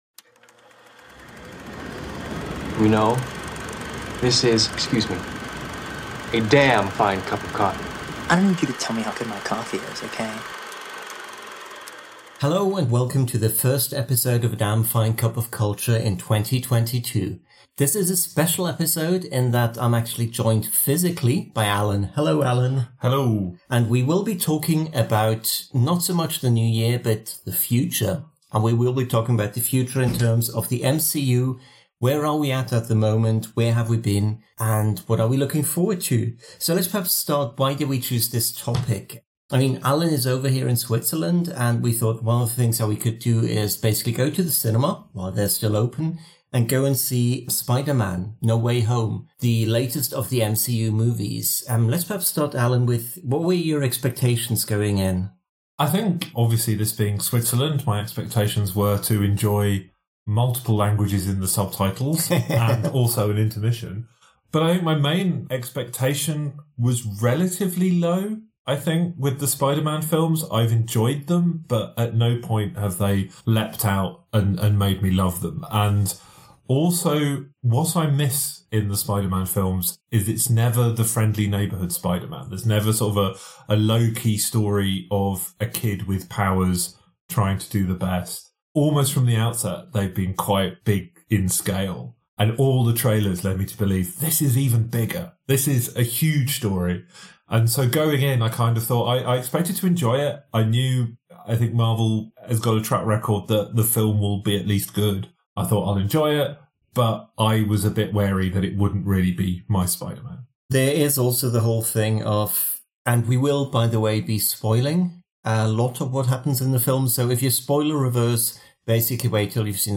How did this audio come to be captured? By the way, this is a Very Special Episode of the Damn Fine Cup of Culture podcast: for the first time ever, it was recorded in front of a live studio audience with everyone on the podcast not only in the same country but the same room, talking into the same microphone!